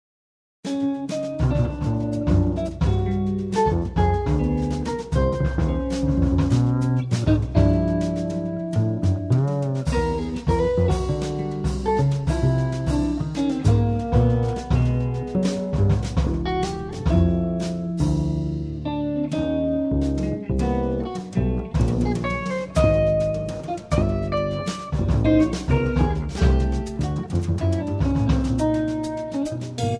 Guitar and Processed Guitar
Acoustic Bass
Drums and Percussion